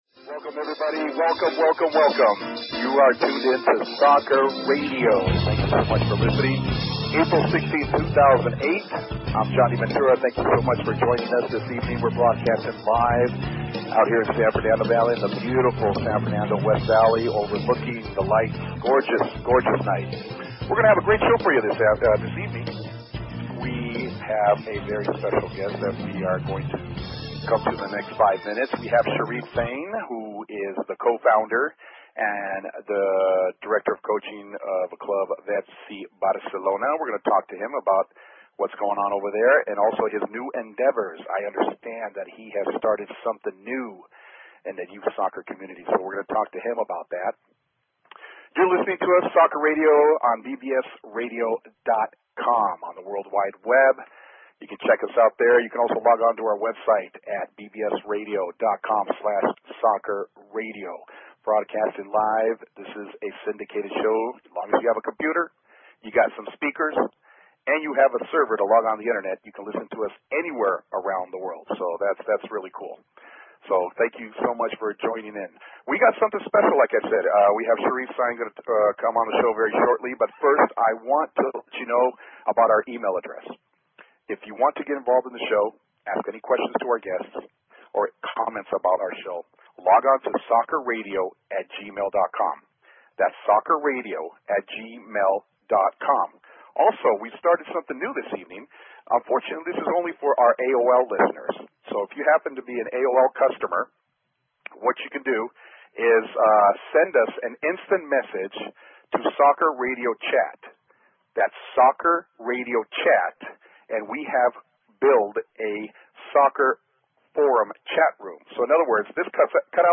Talk Show Episode, Audio Podcast, Soccer_Radio and Courtesy of BBS Radio on , show guests , about , categorized as
Soccer Radio is the first soccer talk show dedicated on the happenings about youth soccer in Southern California. Covering local clubs, teams, coaches and players with top notch information and broadcasting.